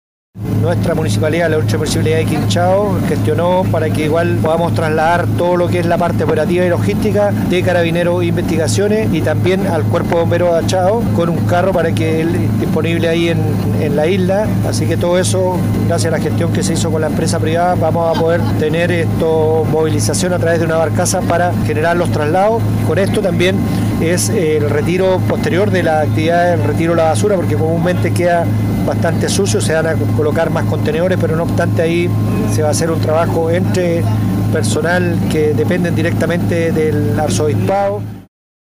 El alcalde de Quinchao, Washington Ulloa, aseguró que apoyarán con una barcaza la parte operativa y logística de Carabineros, la Policía de Investigaciones y el Cuerpo de Bomberos de Achao.